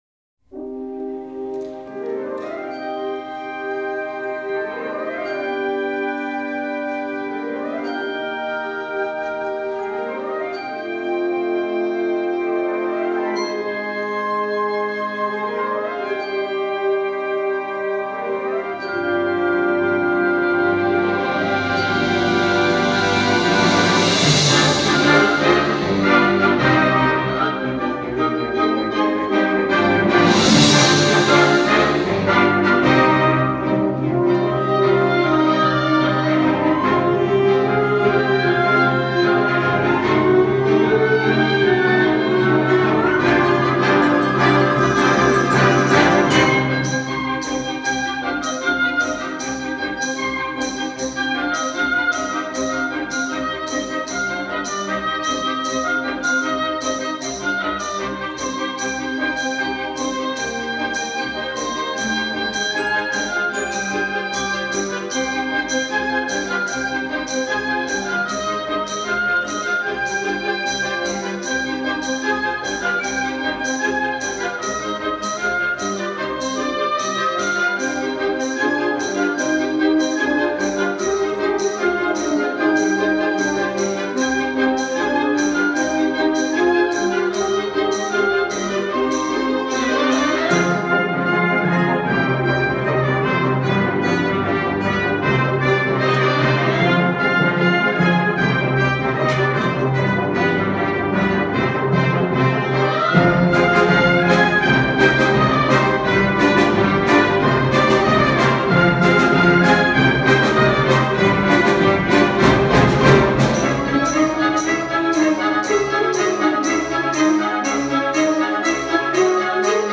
Ensemble: Band/Wind Ensemble